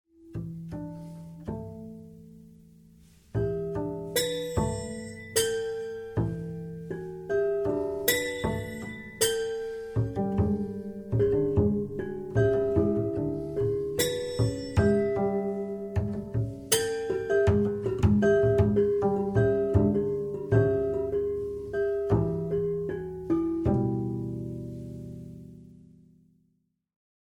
at Secret House Studio, Amsterdam
contrabajo
piano preparado